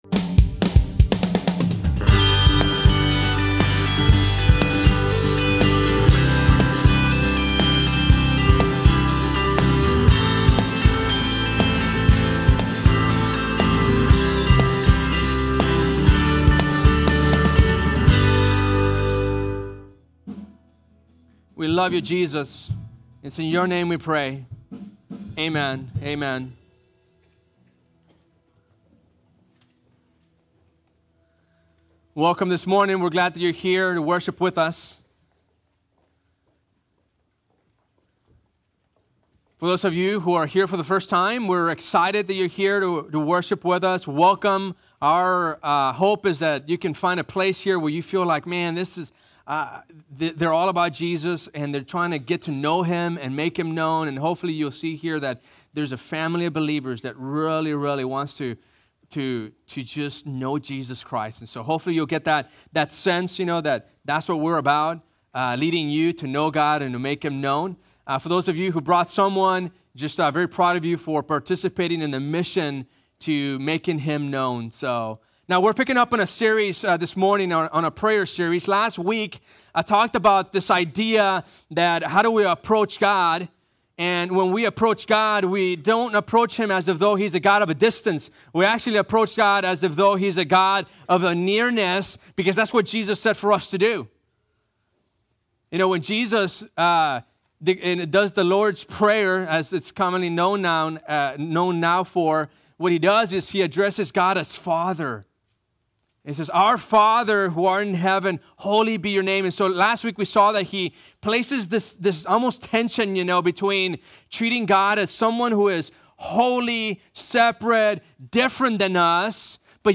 Two Rivers Bible Church - Sermons
Prayer - When Praying Doesn't Work 3 part sermon series on prayer: Everyone knows it's important but not everyone knows how to do it.